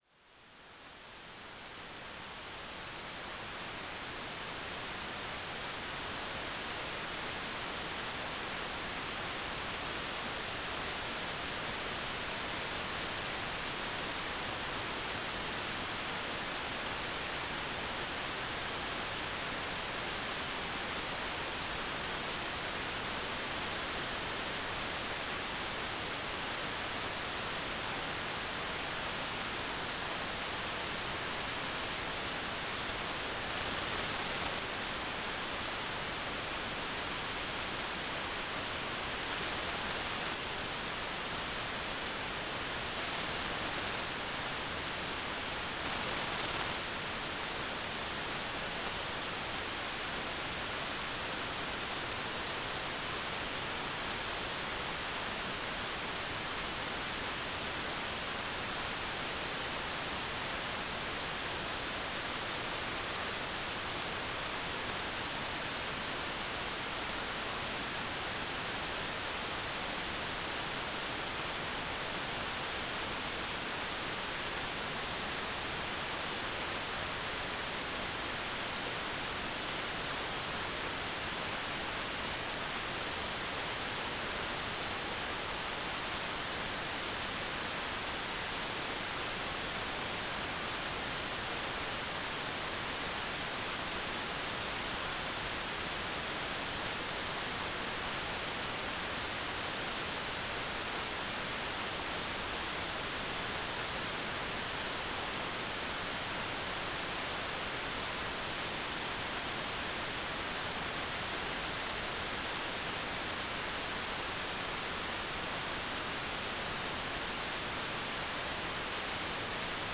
"station_name": "South Australia - UHF",
"transmitter_mode": "CW",